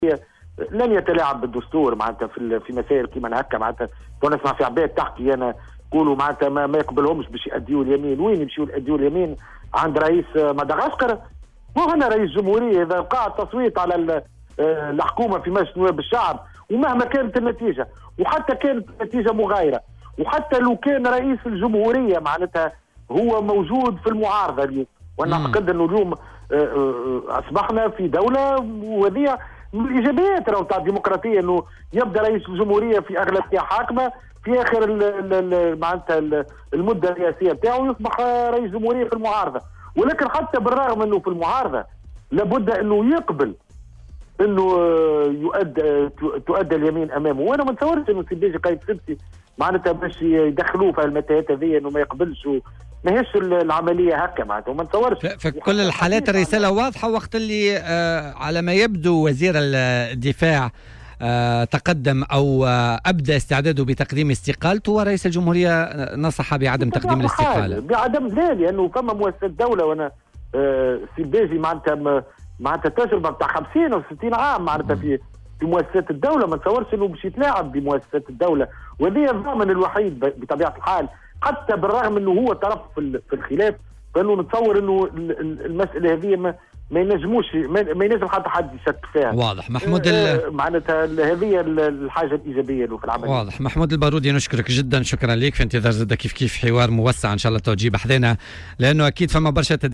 وأكد في مداخلة مع "بوليتيكا" أن رئيس الجمهورية موجود في المعارضة اليوم، وفق تعبيره. واعتبر أن هذا الأمر من ايجابيات الديمقراطية حيث يبدأ الرئيس بأغلبية حاكمة وينهي ولايته الرئاسية وهو في المعارضة. وتابع أنه على رئيس الجمهورية قبول الحكومة بعد التحوير لأداء اليمين.